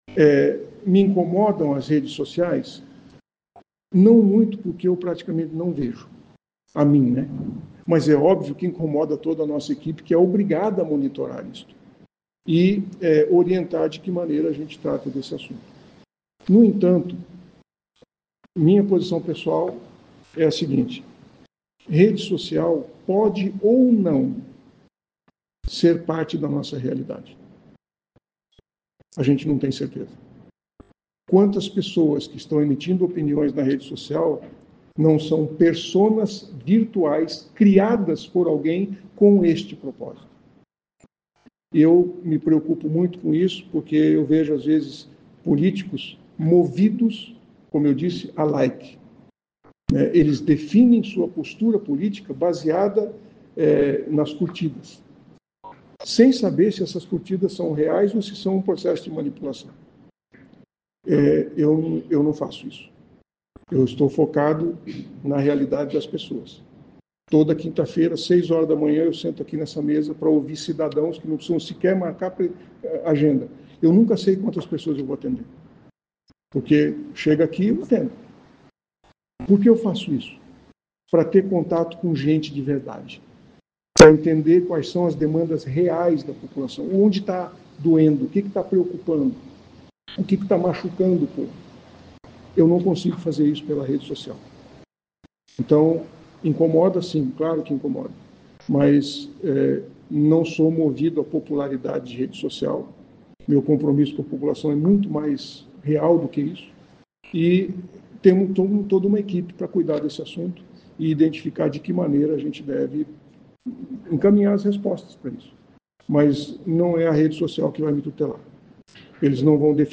Prefeito Sílvio Barros reúne a imprensa para tirar dúvidas sobre IPTU